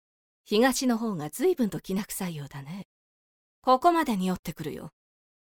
【サンプルセリフ】
ゆったりした大人の女性が良いなと思って設定させていただきました。